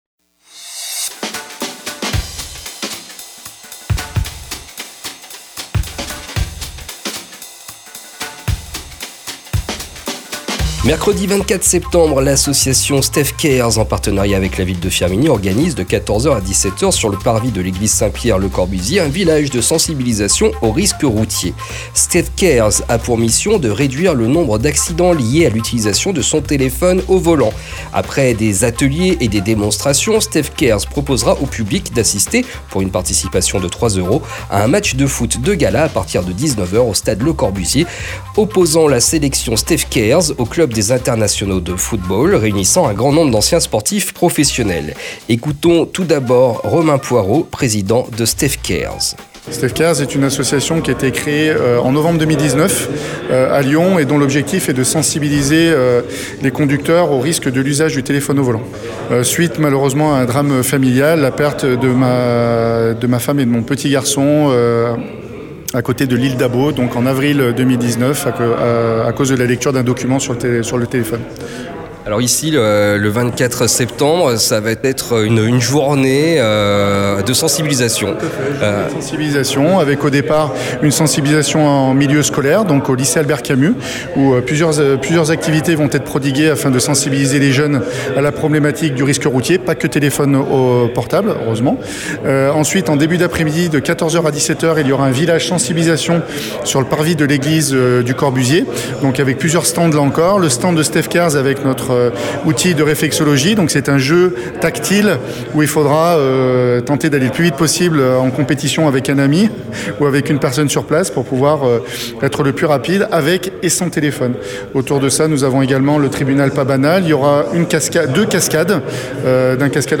Rencontre aujourd’hui sur Radio Ondaine, à 11h30